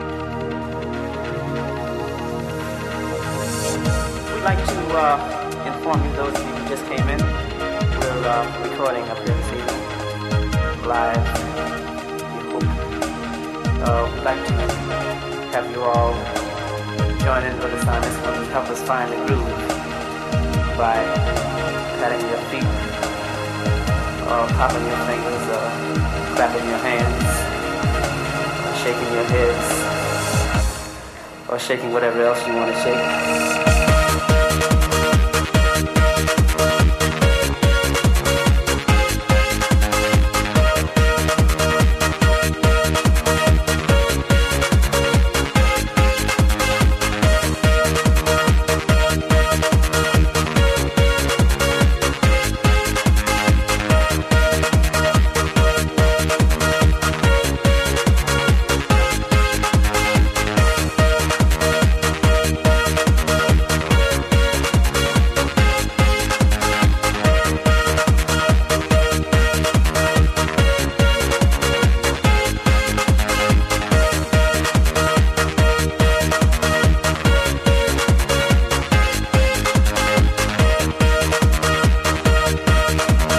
Question Uplifting trance 1999-2002 era Please ID
I was going through my old MiniDisc collection and this came on.